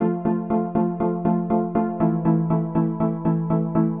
Gear Up (FM Strings) 120BPM.wav